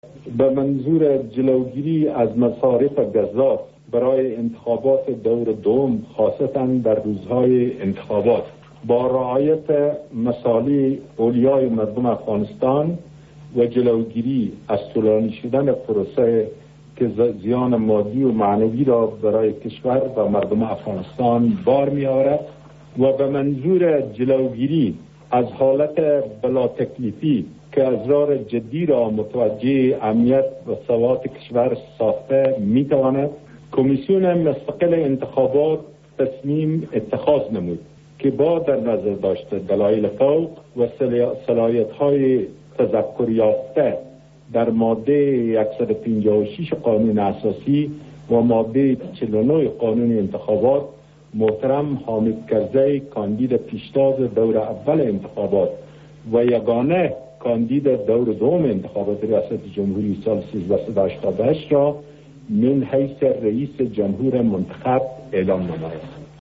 عزیزالله لودین، رئیس کمیسیون مستقل انتخابات افغانستان، خبر پیروزی حامد کرزی را اعلام می‌کند